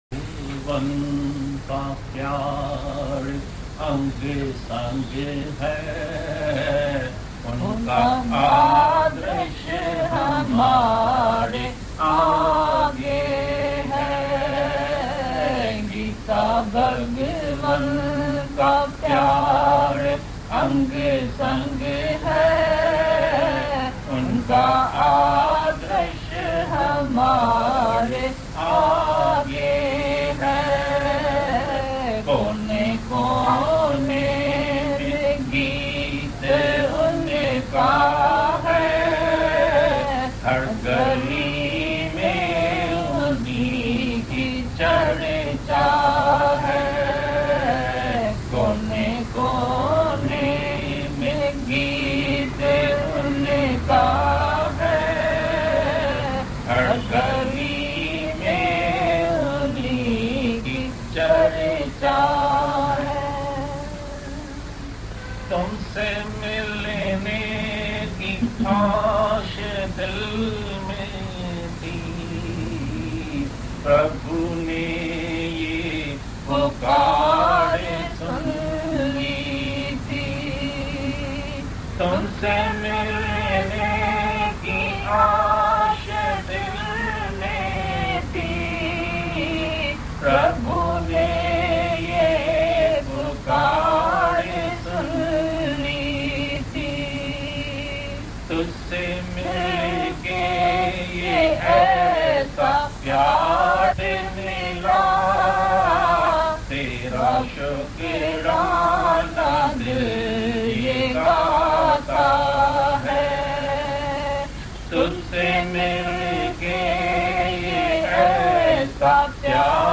Geeta-Bhagwan-ka-Pyar-Ang-Sang-Hai-Unka-Bhajan.mp3